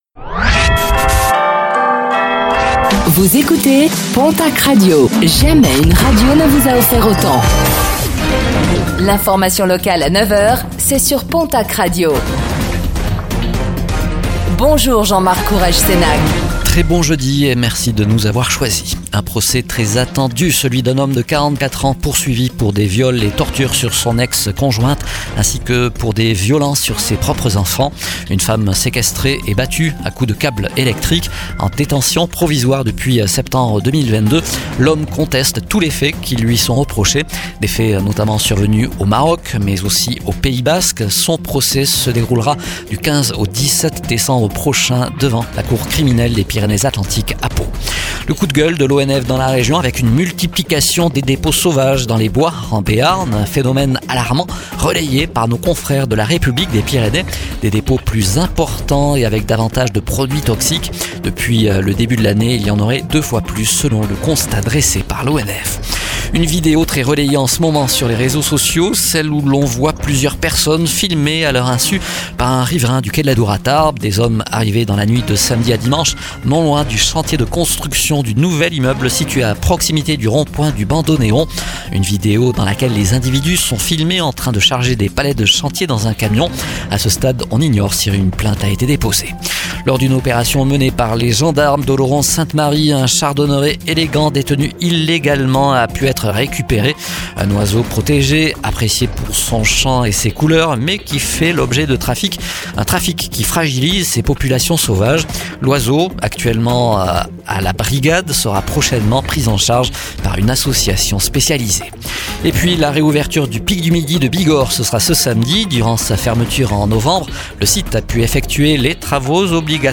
09:05 Écouter le podcast Télécharger le podcast Réécoutez le flash d'information locale de ce jeudi 04 décembre 2025